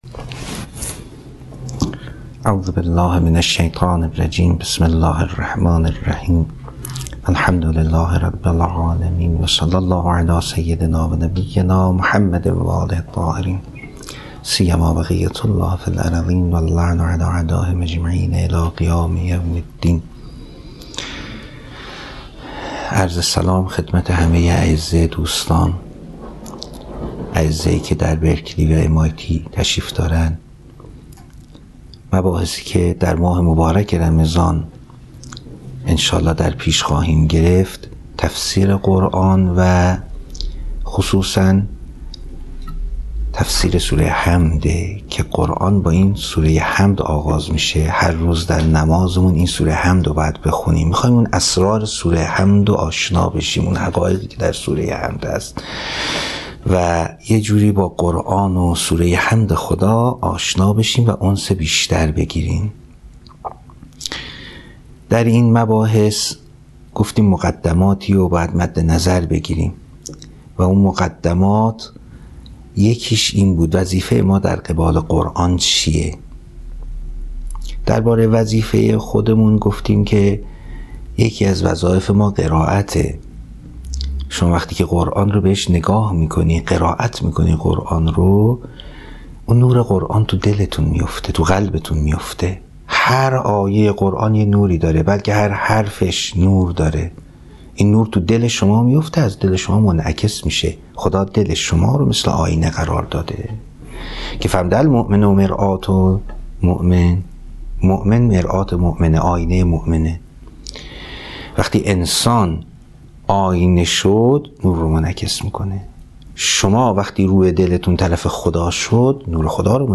درس تفسیر قرآن